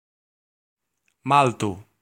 Ääntäminen
Synonyymit Malta Island Republic of Malta Ääntäminen US UK : IPA : /ˈmɔːl.tə/ US : IPA : /ˈmɑl.tə/ IPA : /ˈmɔl.tə/ Haettu sana löytyi näillä lähdekielillä: englanti Käännös Ääninäyte Erisnimet 1.